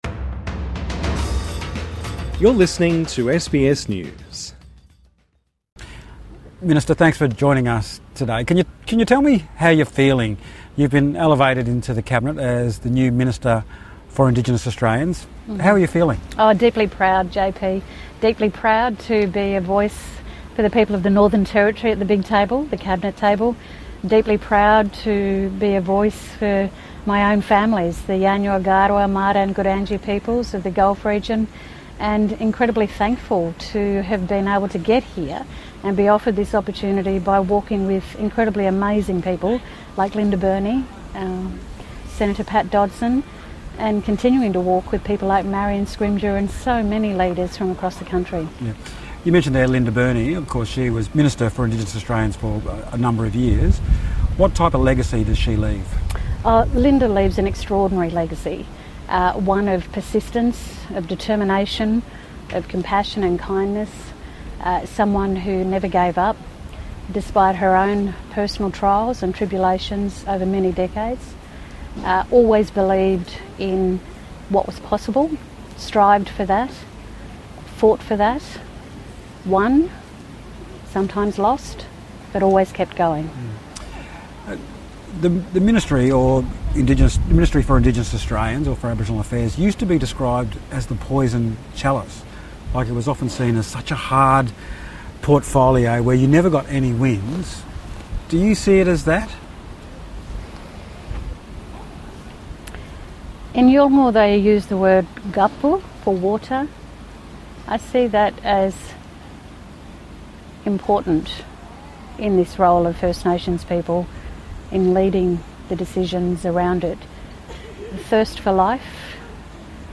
INTERVIEW: Senator Malarndirri McCarthy